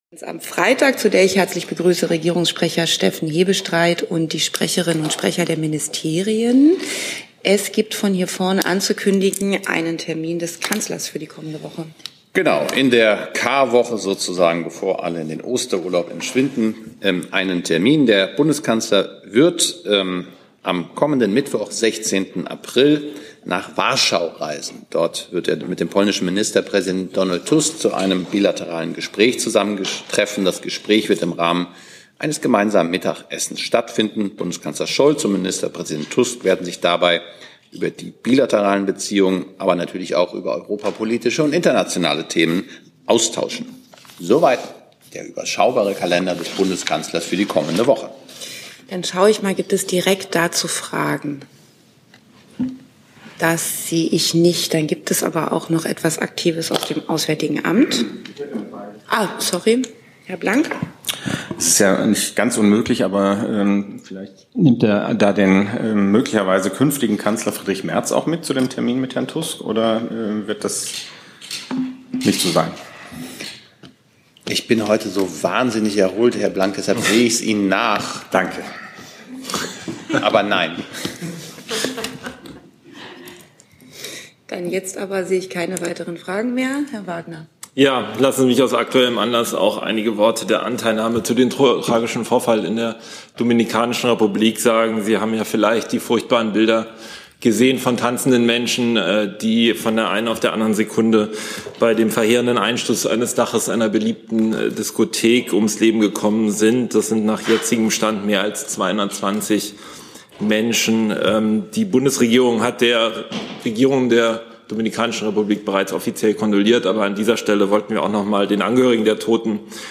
Komplette Regierungspressekonferenzen (RegPK) und andere Pressekonferenzen (BPK) aus dem Saal der Bundespressekonferenz.